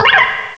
sewaddle.aif